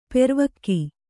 ♪ pervakki